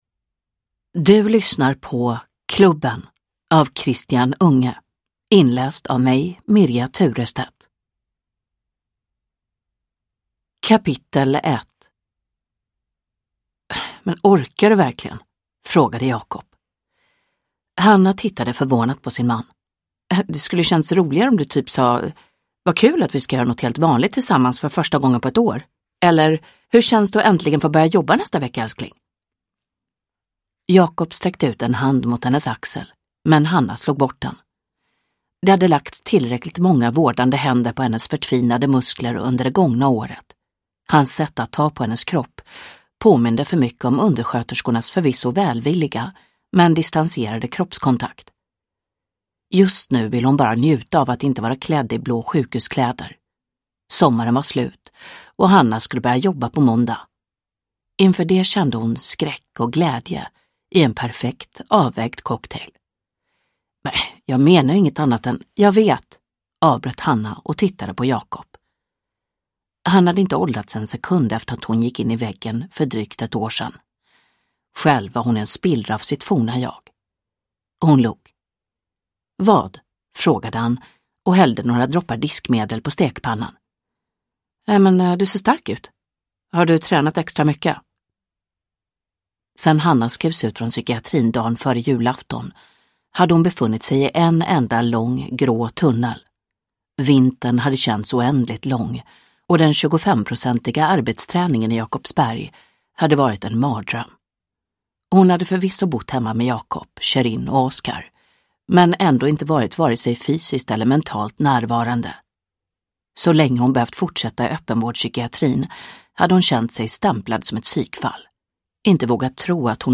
Klubben (ljudbok) av Christian Unge